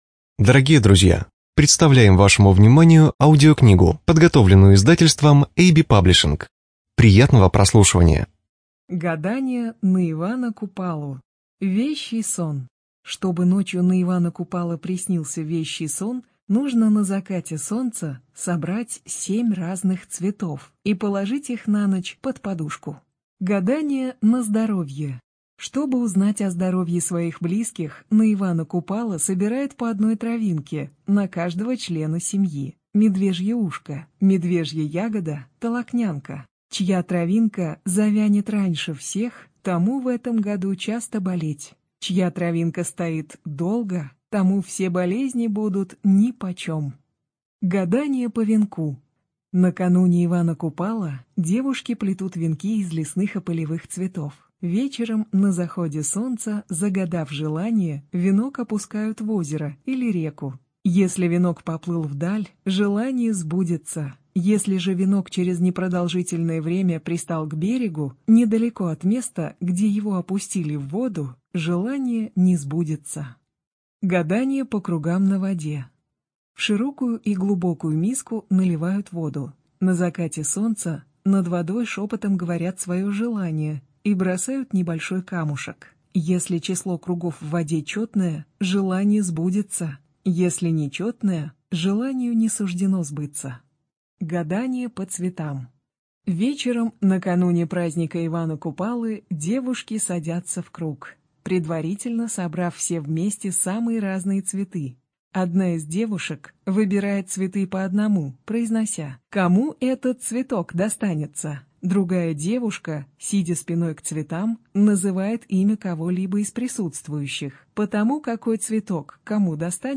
Студия звукозаписиAB-Паблишинг